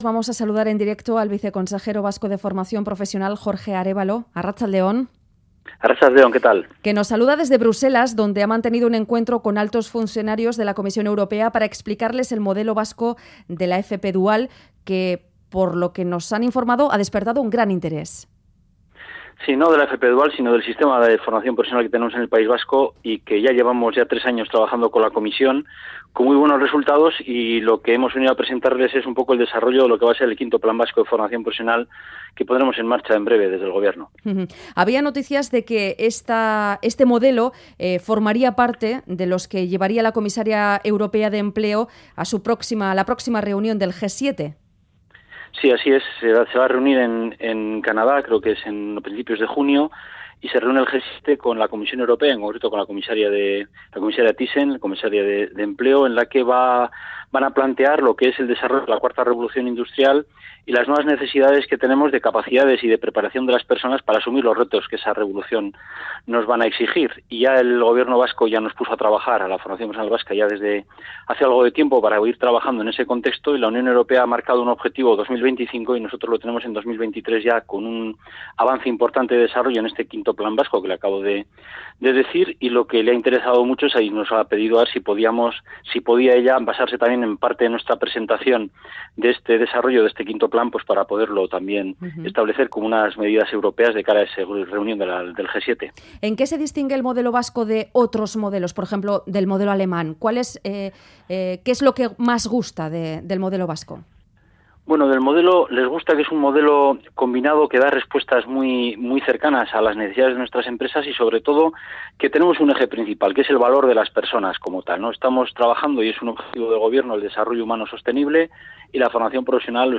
Radio Euskadi GANBARA 'Les gusta que sea un modelo combinado que da respuestas cercanas' Última actualización: 21/03/2018 22:54 (UTC+1) Entrevista en 'Ganbara' al viceconsejero vasco de Formación Profesional Jorge Arevalo.